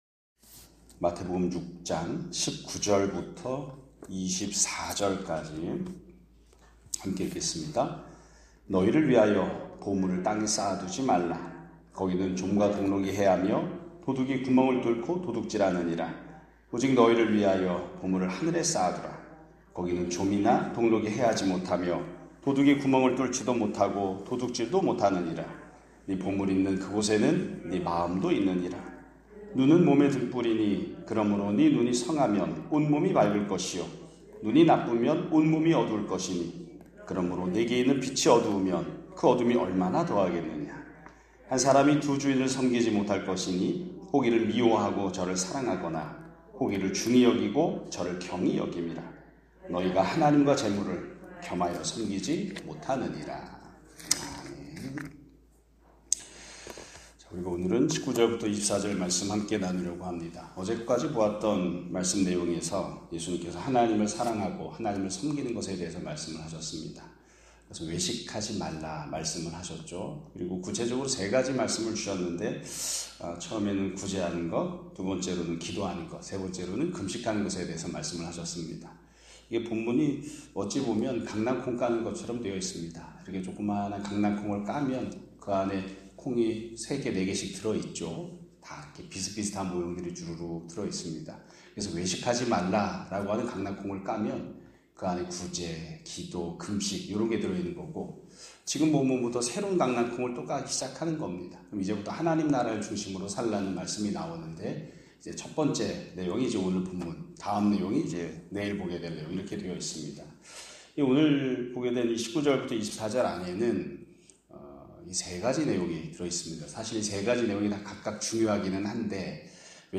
2025년 6월 18일(수요일) <아침예배> 설교입니다.